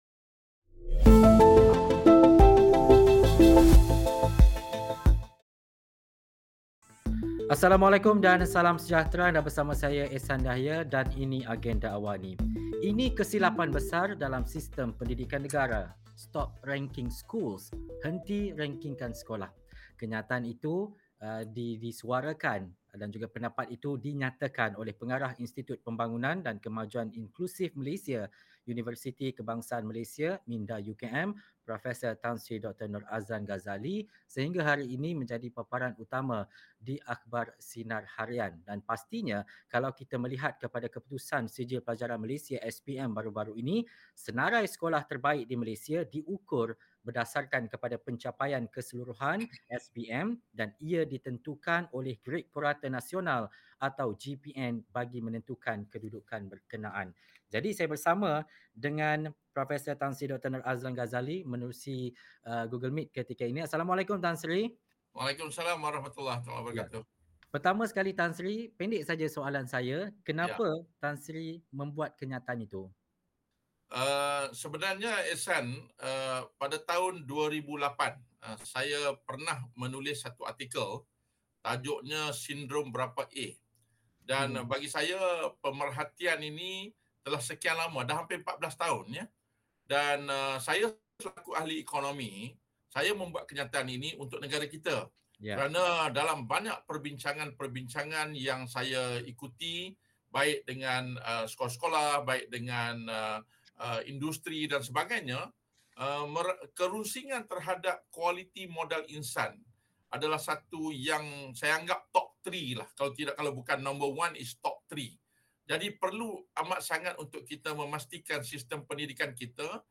Guna ranking untuk menilai prestasi sekolah, apa kesannya terhadap sistem pendidikan di Malaysia? Bagaimana pula dengan jurang prestasi murid di sekolah bandar dan luar bandar? Diskusi 8.30 malam